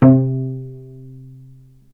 healing-soundscapes/Sound Banks/HSS_OP_Pack/Strings/cello/pizz/vc_pz-C3-ff.AIF at 61d9fc336c23f962a4879a825ef13e8dd23a4d25
vc_pz-C3-ff.AIF